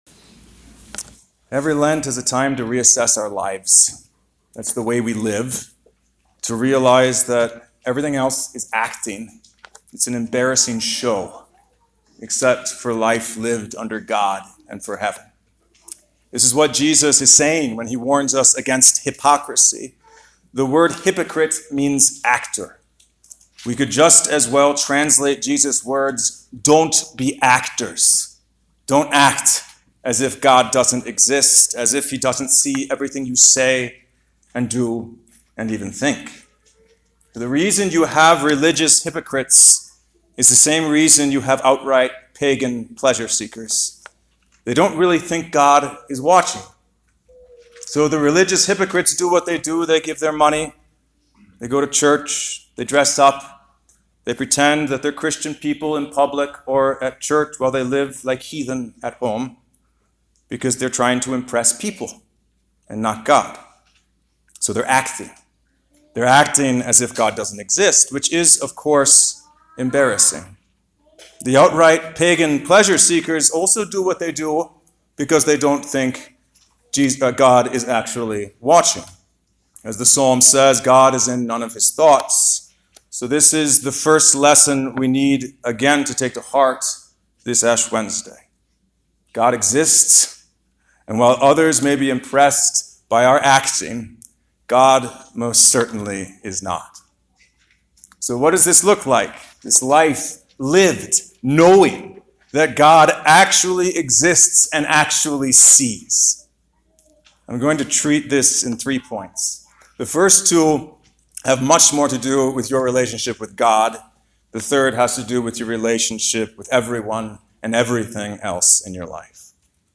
2-17-21 Ash Wednesday • Mount Hope Lutheran Church & School